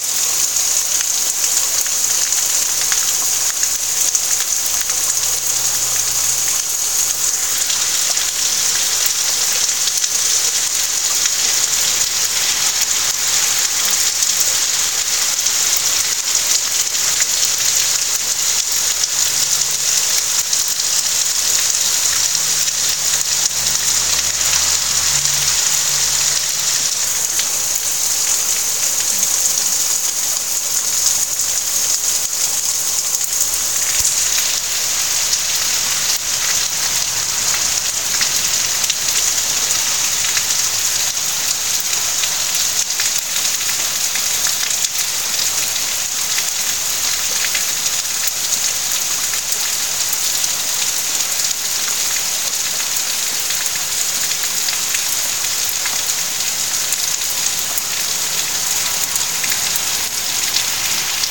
Really heavy rain